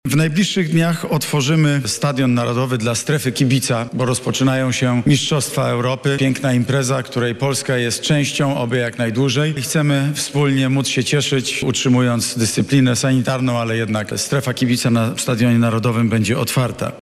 Na koncertach i w obiektach sportowych dostępne będzie 50% miejsc – mówi Mateusz Morawiecki, Prezes Rady Ministrów.